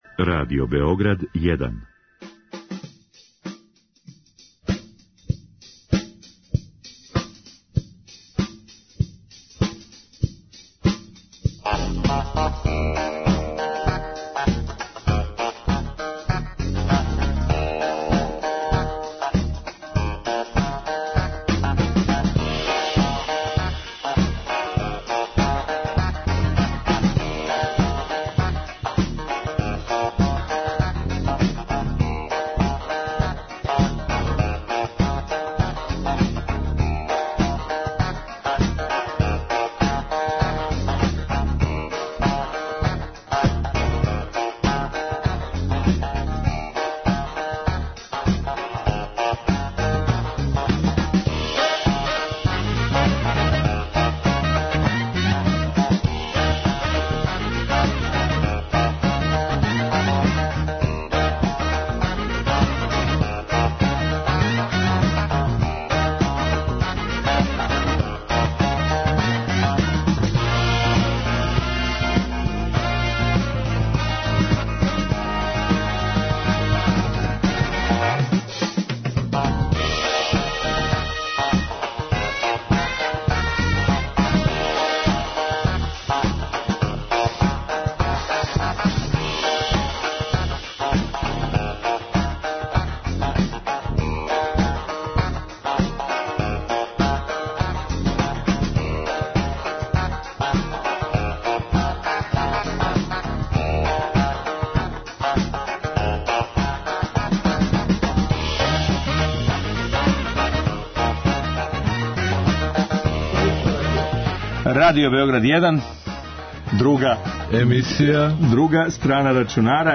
О томе ће причати млади експерти из CityExperta.